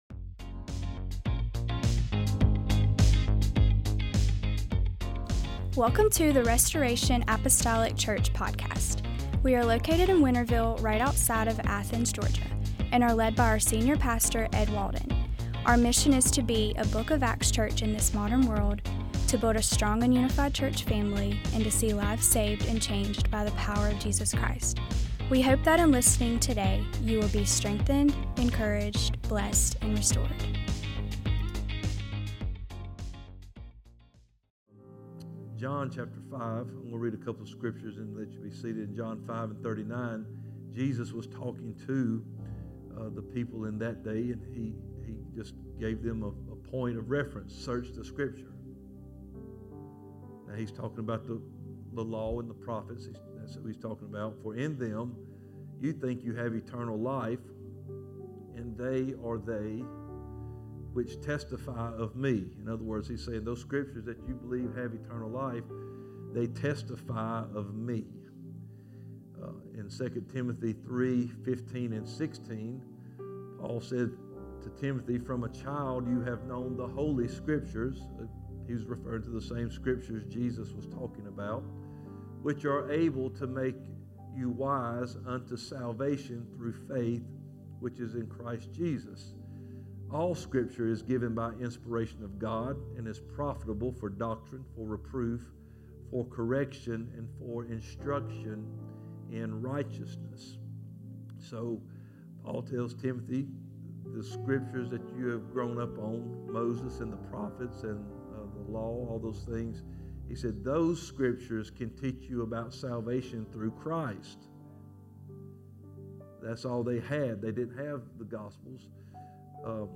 MDWK Bible Study